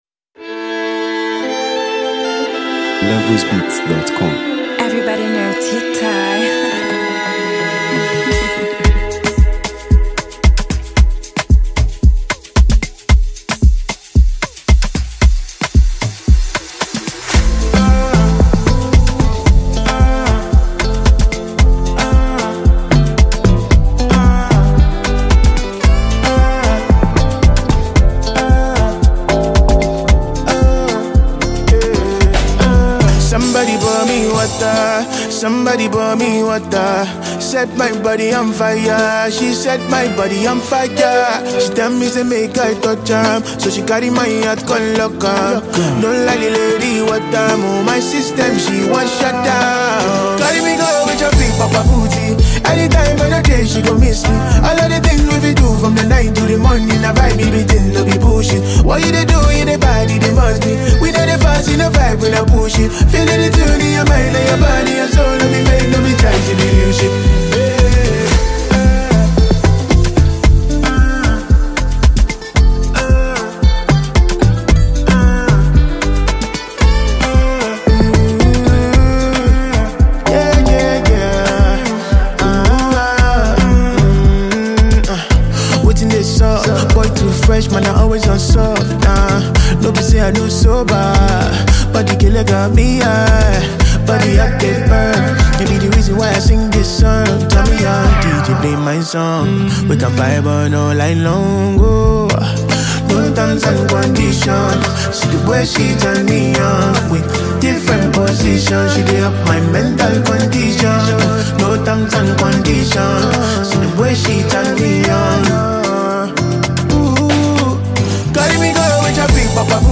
melodious vocals
On this vibrant and feel-good track
adds his signature sweet melodies